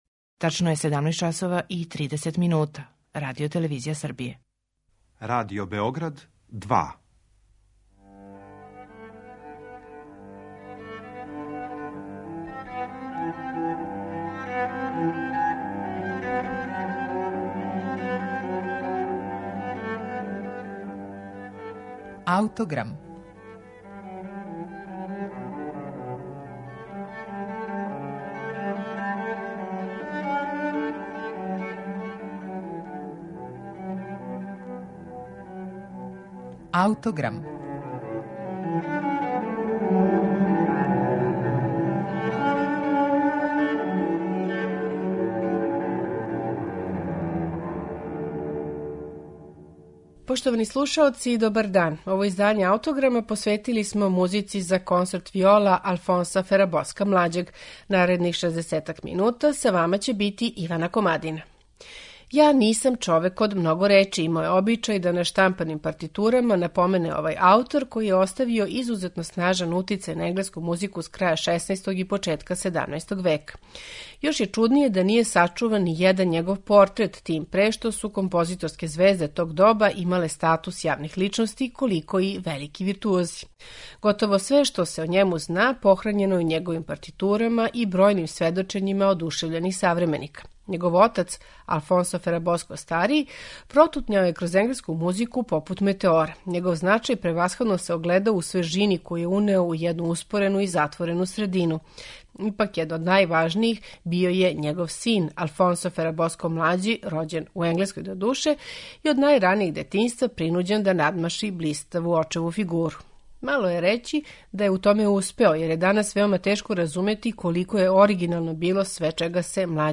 Алфонсо Ферабоско млађи: Музика за консорт виола
Ипак, његово највеће наслеђе су фантазије за виоле. У данашњем Аутограму представићемо Ферабоскове фантазије, паване и консорте за виоле у интерпретацији ансамбла „Хесперион XXI", под управом Жордија Саваља.